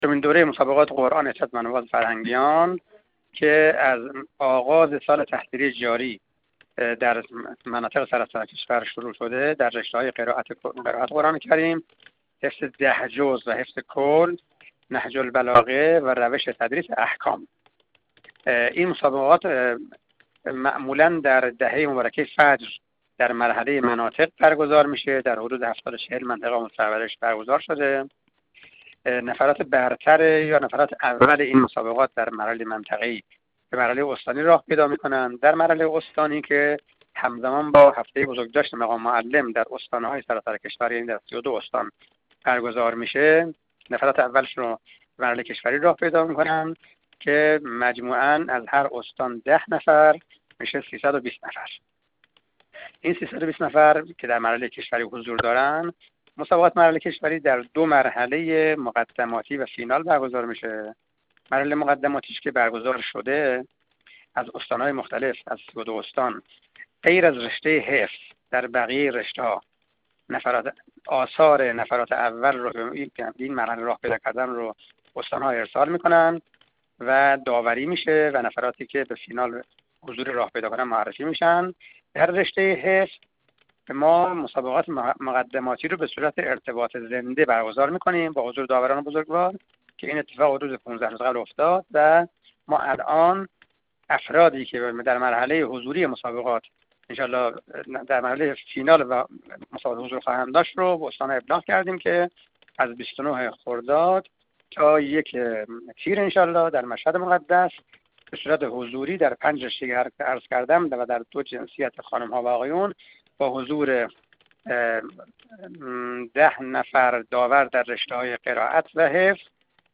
میکائیل باقری، مدیرکل قرآن، عترت و نماز وزارت آموزش و پرورش